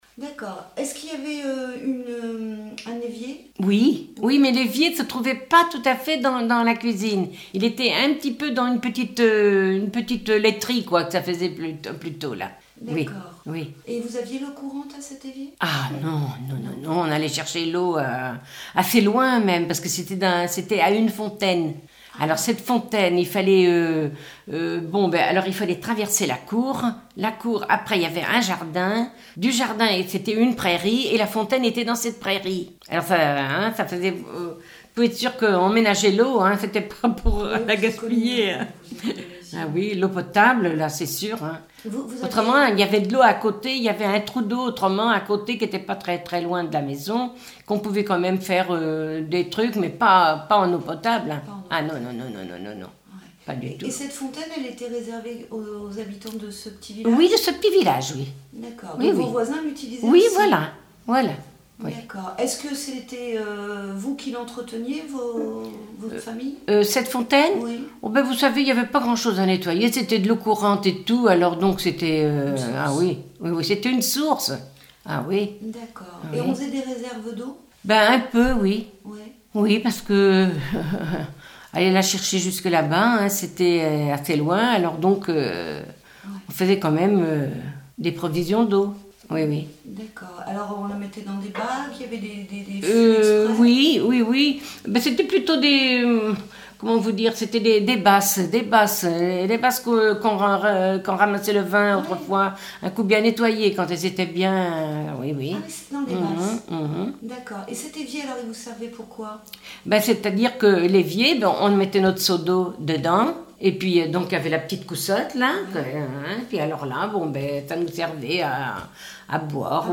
Témoignages sur la vie à la ferme